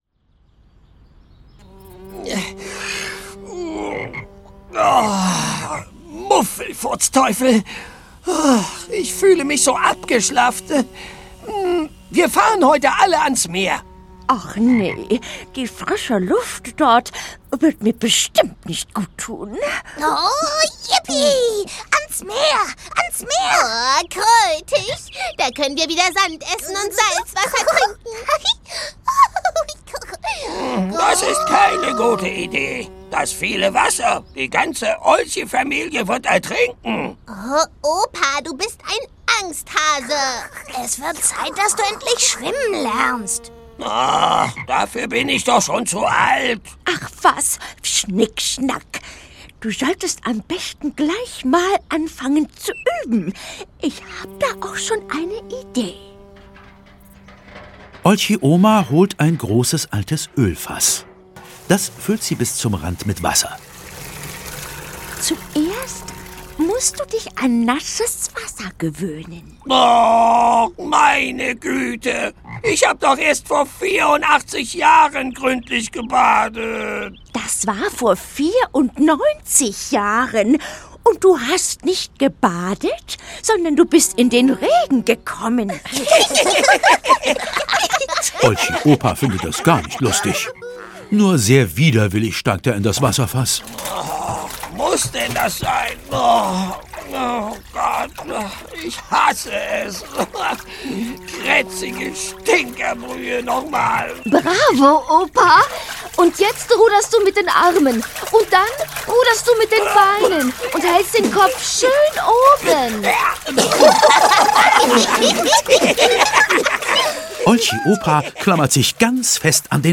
Die Olchis sind da - Erhard Dietl - Hörbuch